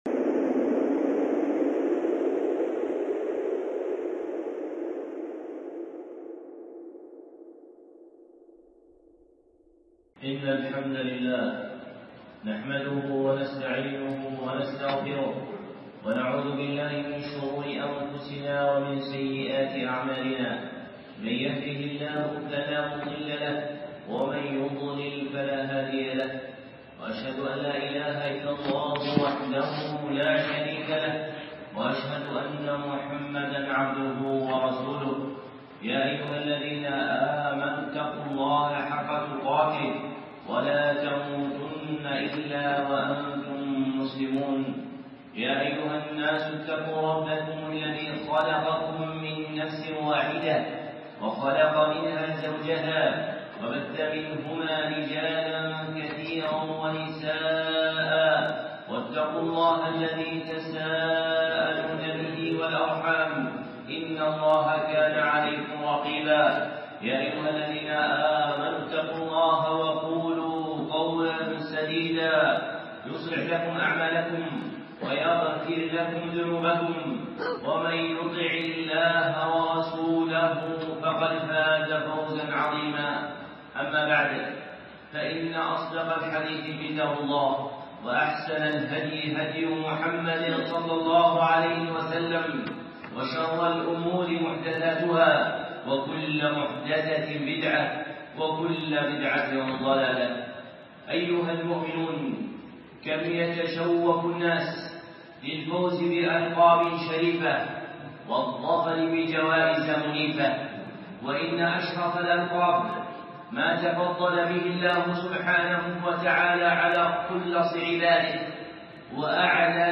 خطبة
الخطب المنبرية